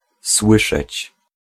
Ääntäminen
Vaihtoehtoiset kirjoitusmuodot (vanhentunut) heare (vanhentunut) heere (rikkinäinen englanti) 'ear Synonyymit listen read (arkikielessä) get Ääntäminen UK : IPA : [hɪər] US : IPA : [hiːɹ] UK : IPA : /hɪə(ɹ)/ US : IPA : /hɪɚ/